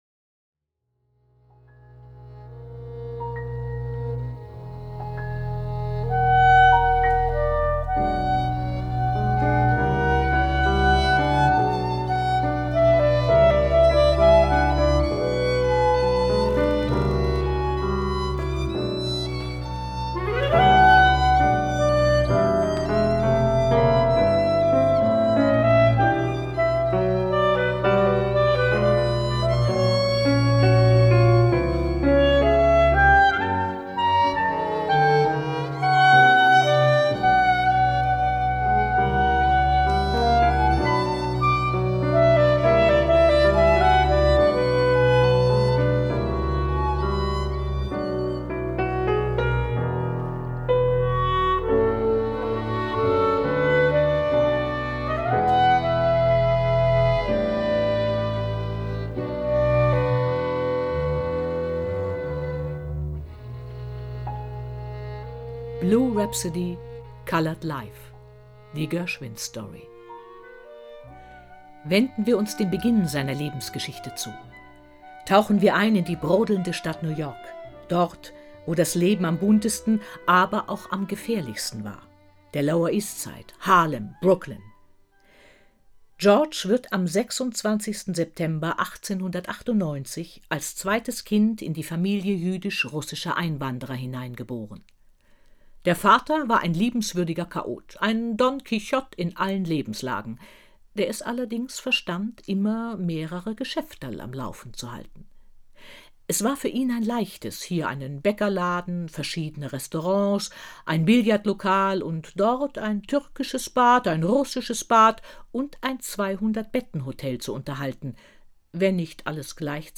Klavier:
Klarinette:
GershwTextMusik.mp3